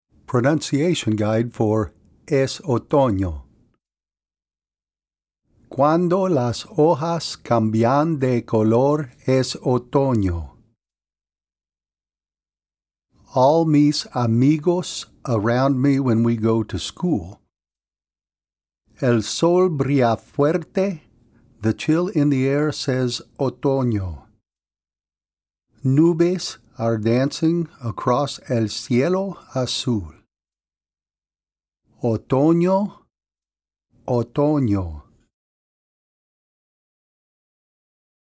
This track is a pronunciation guide for the Spanish words used in the song.
x361_EsOtono-PronunciationGuide.mp3